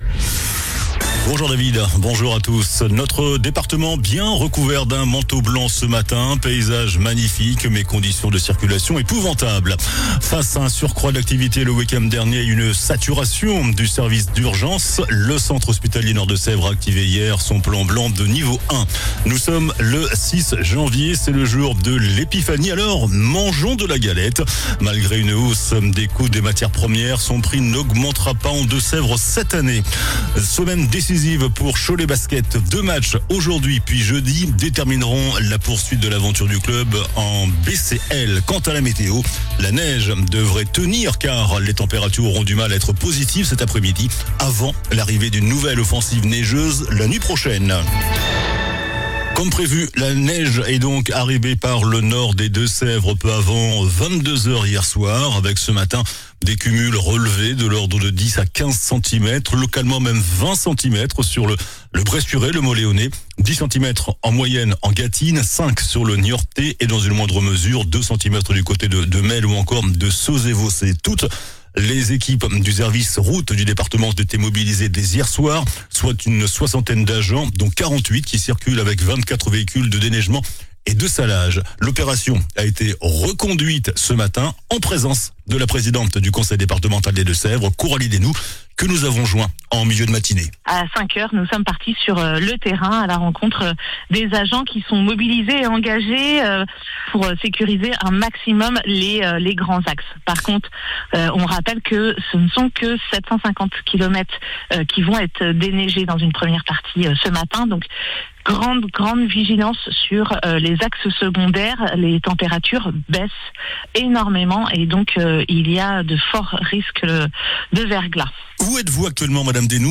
JOURNAL DU MARDI 06 JANVIER ( MIDI )